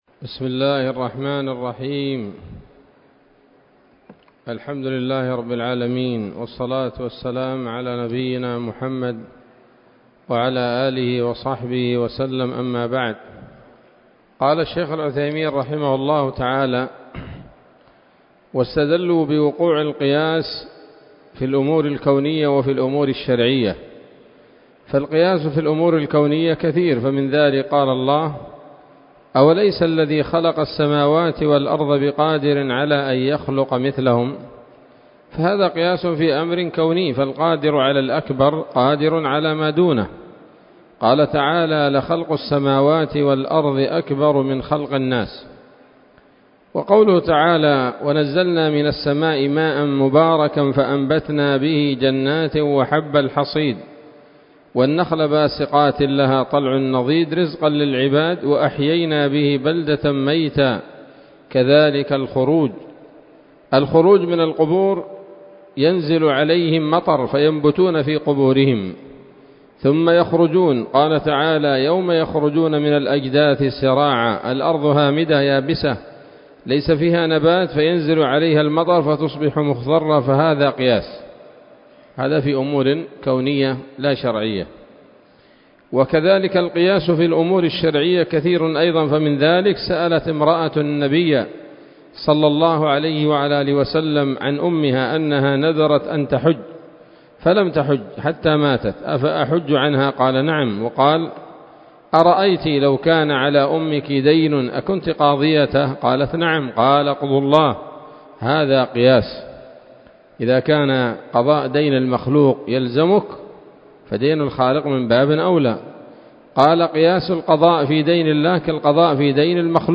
الدرس الستون من شرح نظم الورقات للعلامة العثيمين رحمه الله تعالى